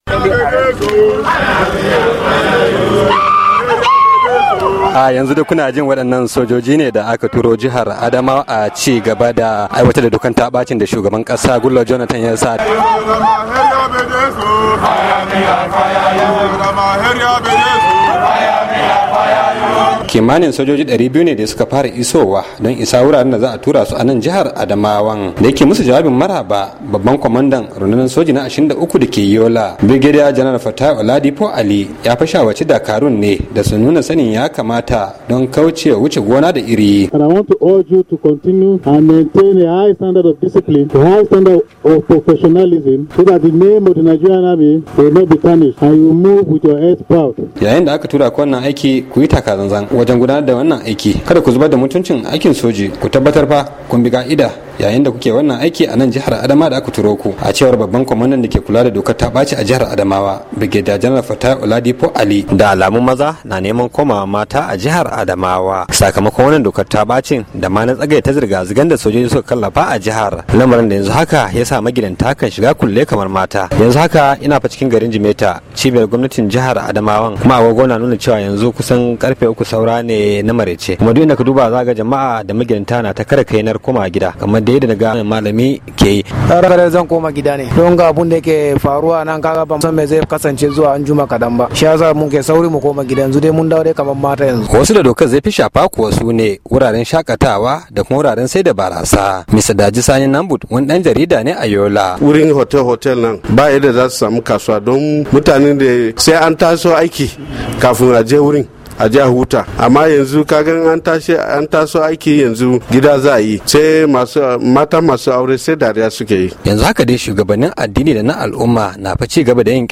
Rohoto Dangane da Girke Sojoji 200 a Adamawa - 2:32